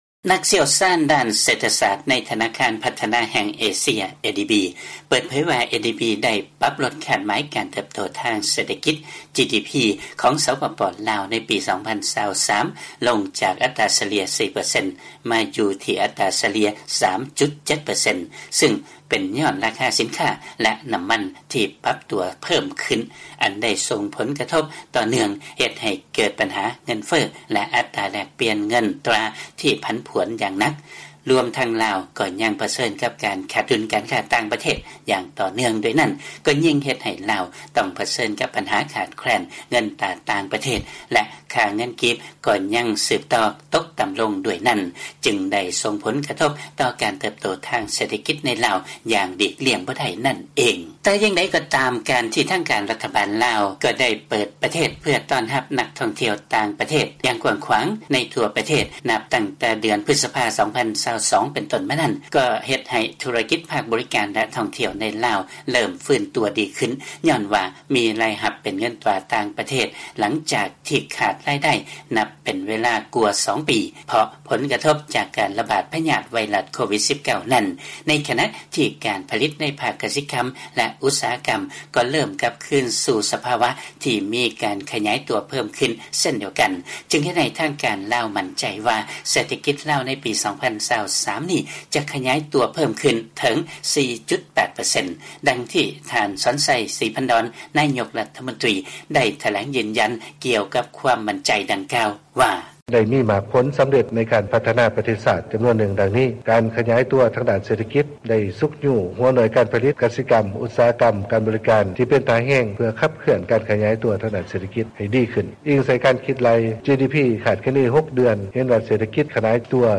ເຊີນຟັງລາຍງານກ່ຽວກັບ ການຄາດຄະເນການເພີ້ມຂຶ້ນຂອງເສດຖະກິດລາວຈາກທະນາຄານ ADB